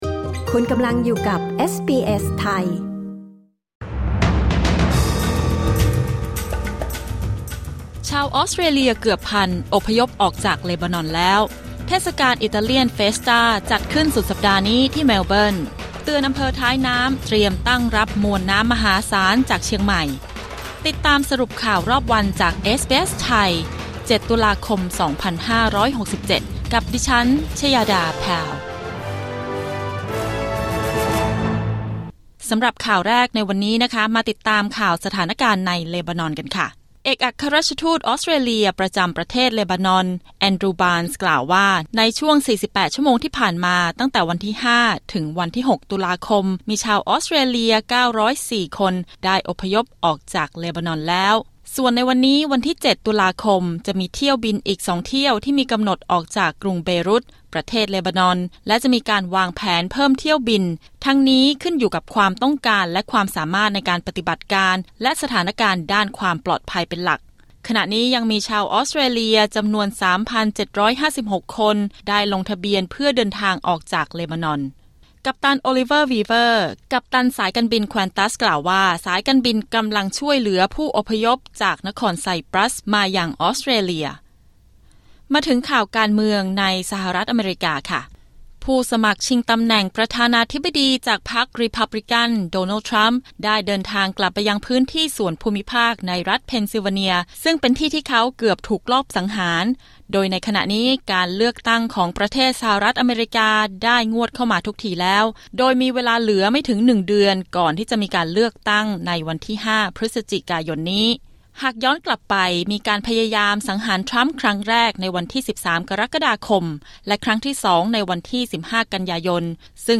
สรุปข่าวรอบวัน 7 ตุลาคม 2567
คลิก ▶ ด้านบนเพื่อฟังรายงานข่าว